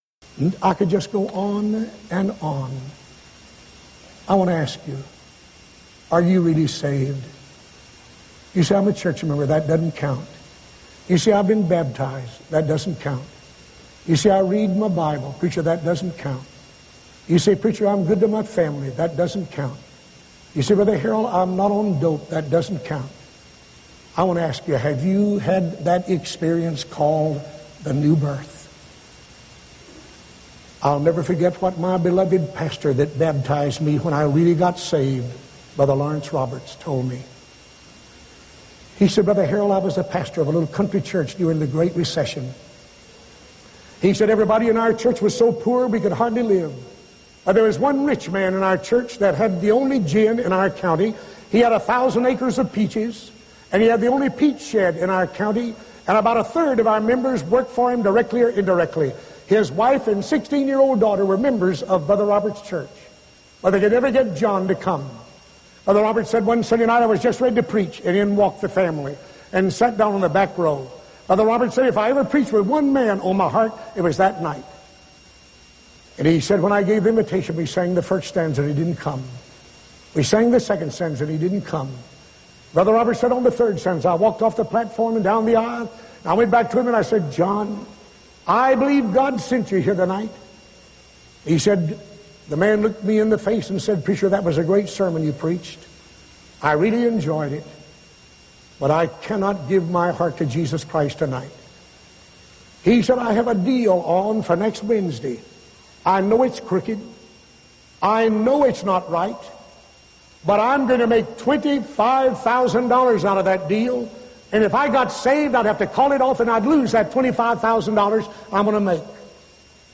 sermon, “God's Three Deadlines.”